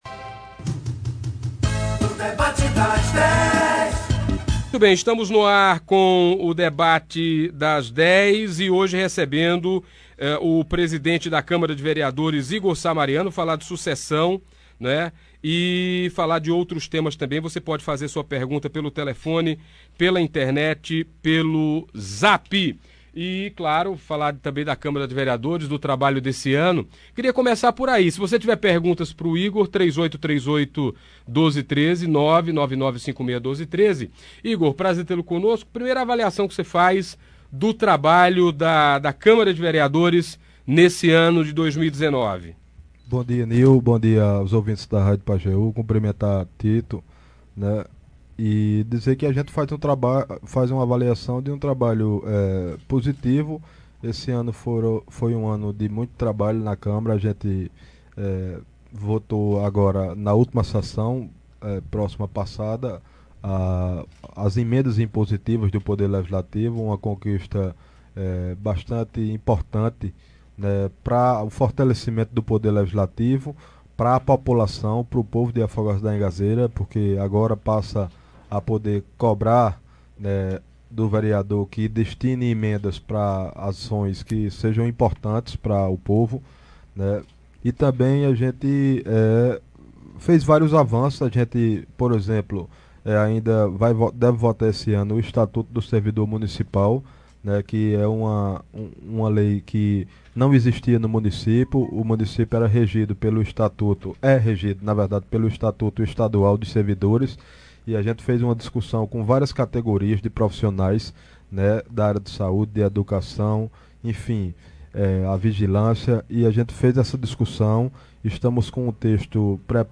Dando continuidade à série que busca ouvir todas as terças-feiras no Debate das Dez da Rádio Pajeú FM, personagens importantes na política de Afogados da Ingazeira, nesta terça-feira (10) foi a vez do presidente da Câmara de Vereadores e pré-candidato a prefeito, Igor Sá Mariano falar sobre os rumos que deve tomar nas eleições de 2020. Ouça abaixo a íntegra do debate de hoje: